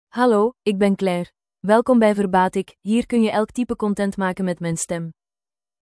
FemaleDutch (Belgium)
Claire is a female AI voice for Dutch (Belgium).
Voice sample
Claire delivers clear pronunciation with authentic Belgium Dutch intonation, making your content sound professionally produced.